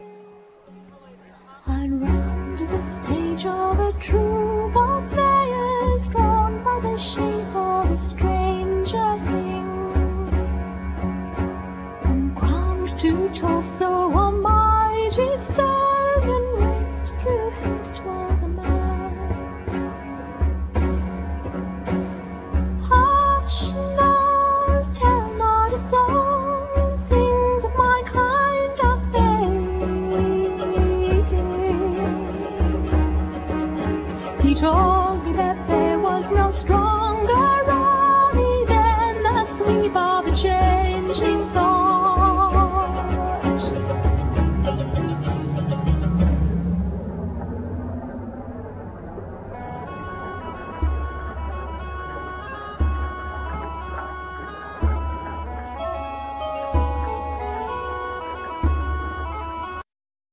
Vocal,Mandolin,Firesticks,Bells,Chimes,Keyboards
Keyboard,Bass,Tablas,Angel harp,Effects
Percussions
Oboe